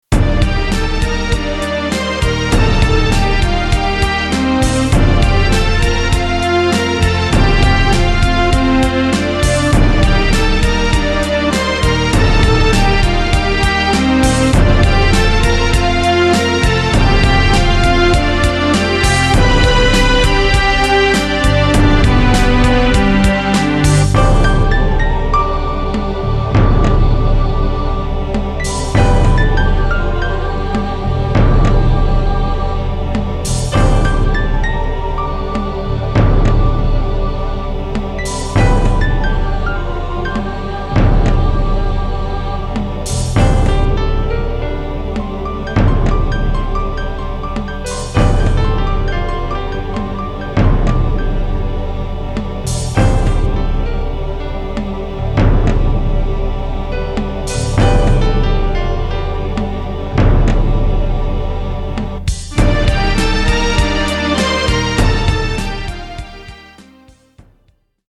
【1.30MB　1:08】イメージ：壮大、神秘的